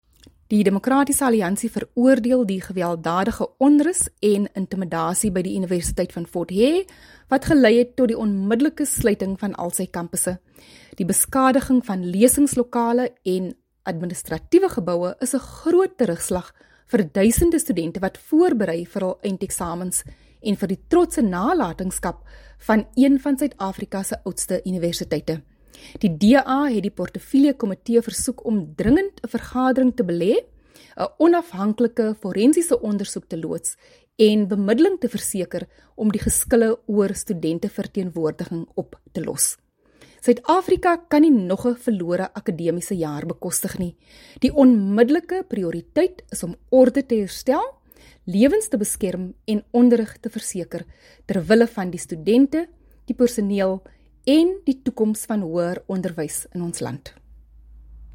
Afrikaans soundbite by Dr Delmaine Christians MP.